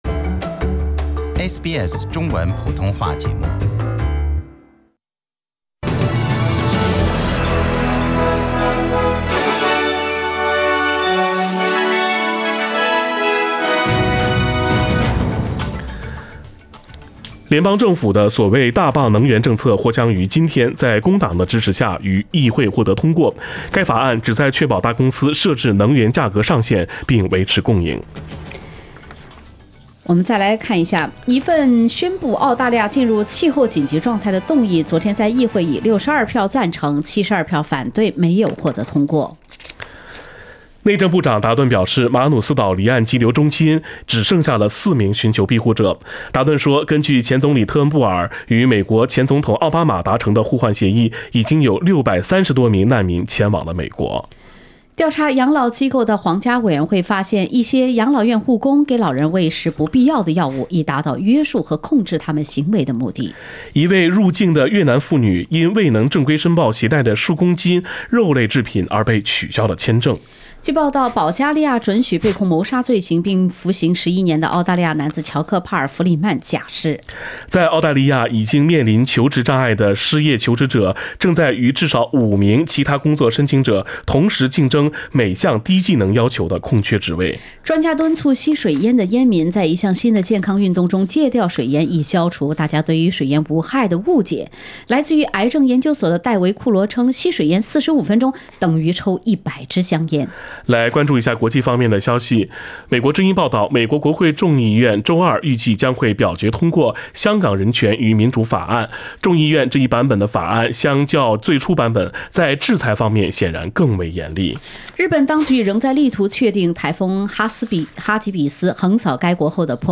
SBS早新闻 （10月16日）
SBS Chinese Morning News Source: Shutterstock